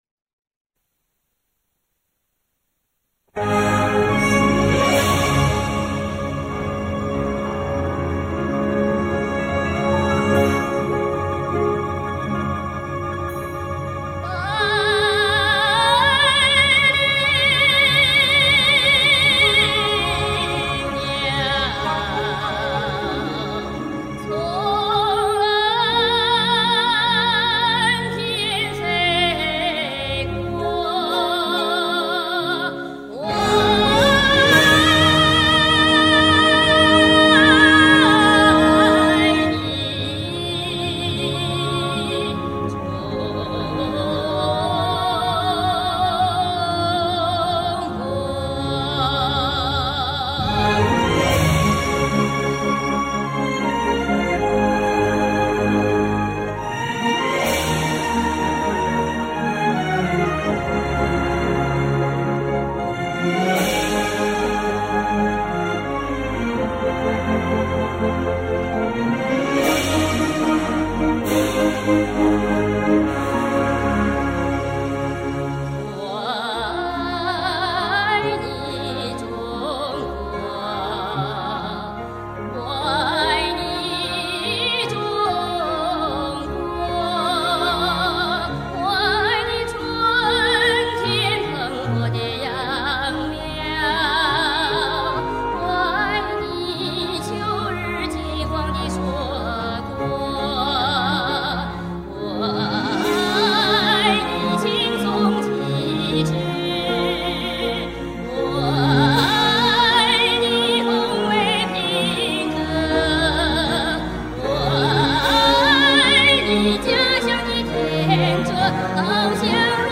有一天突然想起， 何不用CE處理一下， 使自己的歌變成別人的聲音呢？